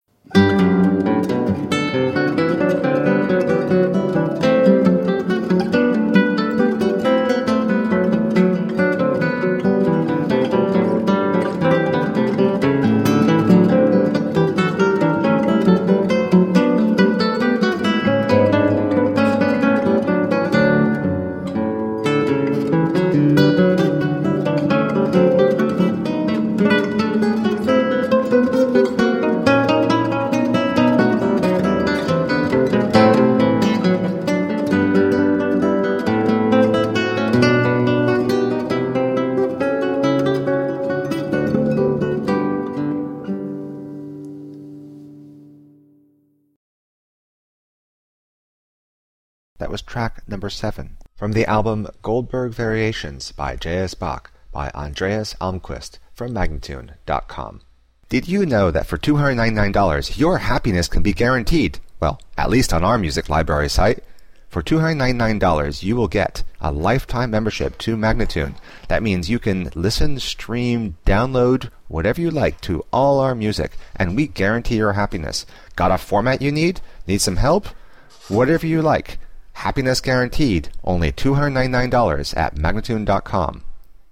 Elegant classical guitar.